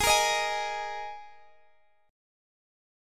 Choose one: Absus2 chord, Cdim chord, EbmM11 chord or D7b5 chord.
Absus2 chord